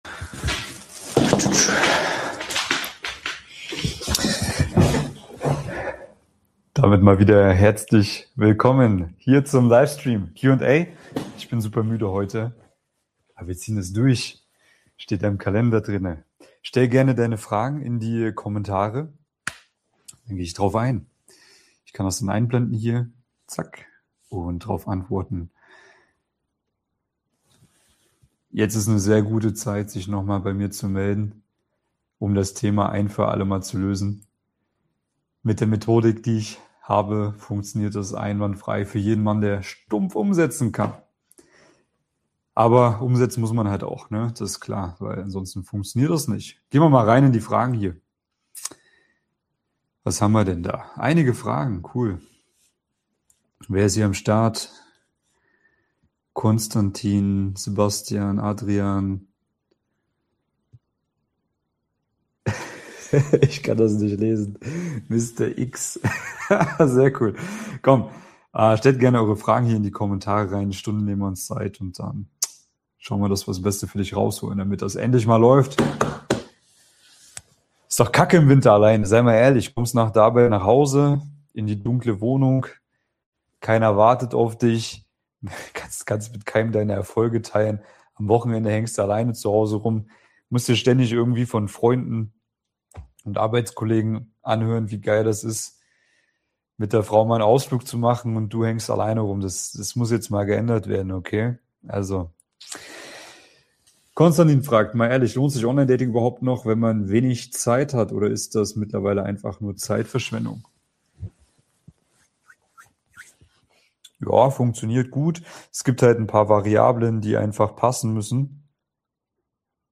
LIVE Q&A – Dating für Unternehmer, Akademiker & IT-Männer Stell deine Fragen jetzt in die Kommentare und ich beantworte sie live im Stream.